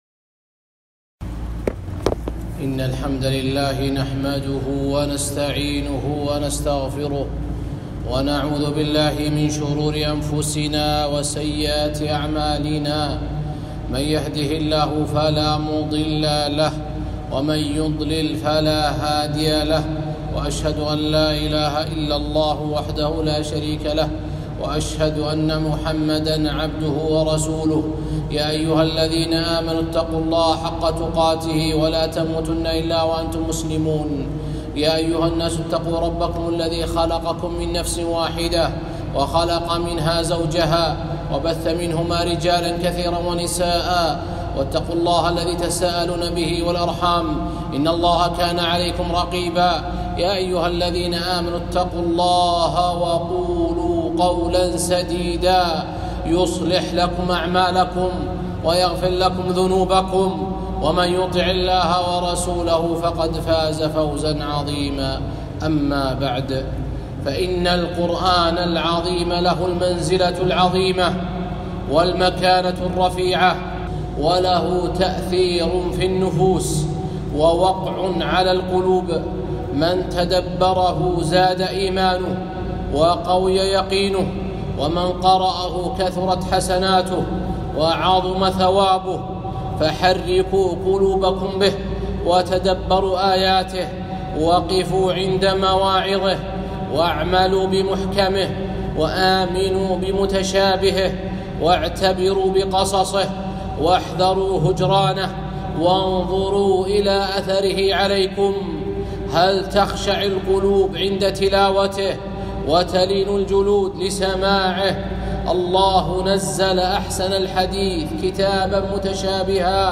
خطبة - تلاوة القرآن ودمع العين